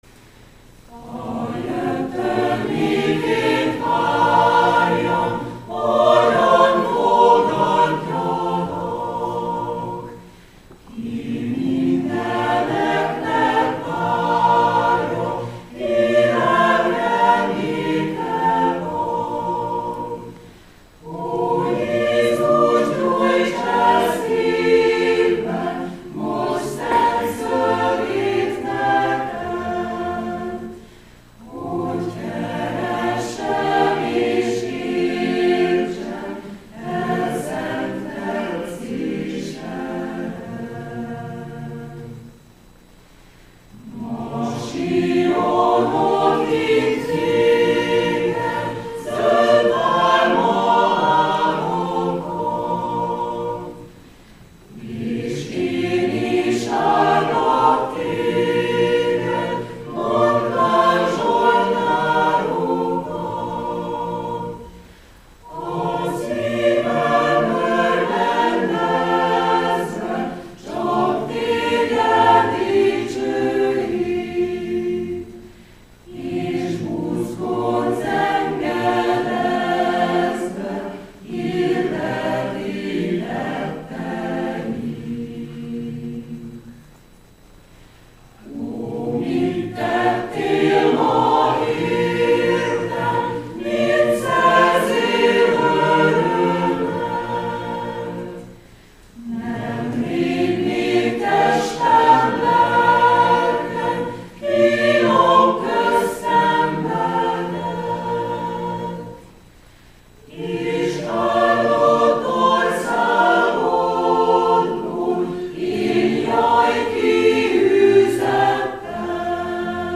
Mienk, reformátusoké az első gyertyagyújtás alkalma. Az énekeskönyv 388. számú énekét énekeljük, amely ide kattinva meghallgatható!